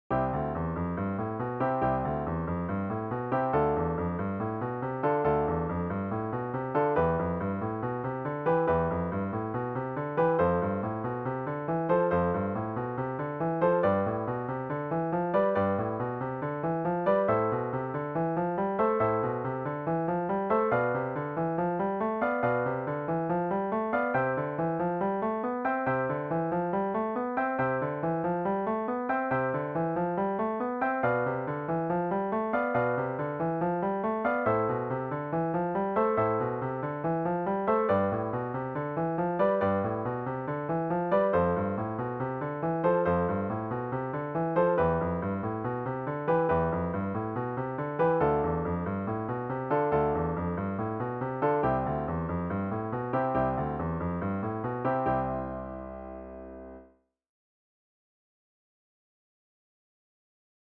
🎹 Piano Technique Exercise